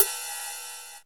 D2 RIDE-11-R.wav